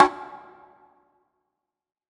WBONGO SLA1J.wav